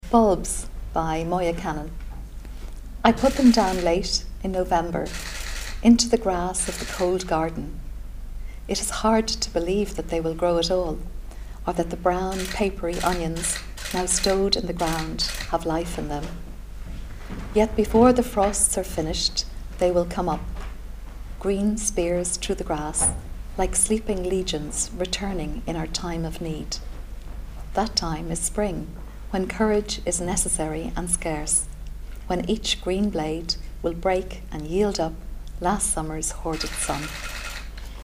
她翻开爱尔兰女诗人莫雅·坎农的诗作《球茎》，即兴朗诵:
戳下方，听大使朗诵：